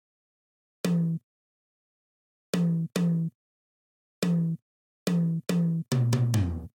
标签： 142 bpm Trance Loops Drum Loops 1.14 MB wav Key : Unknown
声道立体声